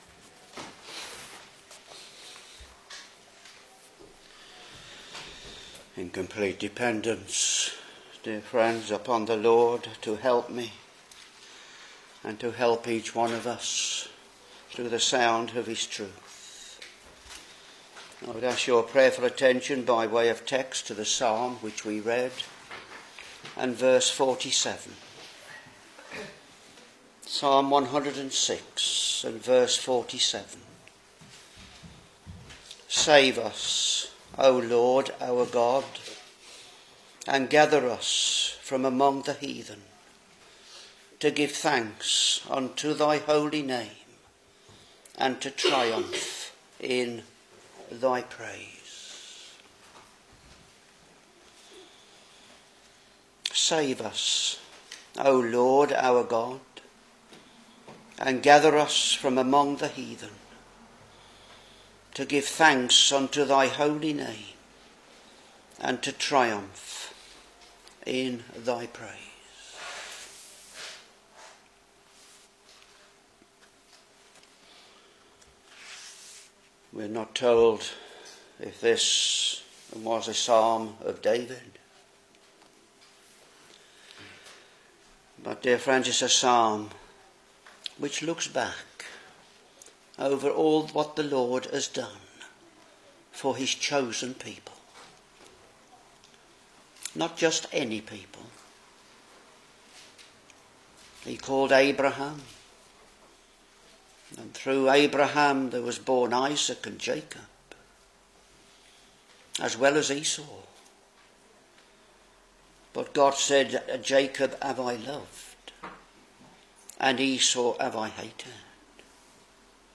Back to Sermons Psalm 106 v.47 Save us, O LORD our God, and gather us from among the heathen, to give thanks unto thy holy name, and to triumph in thy praise.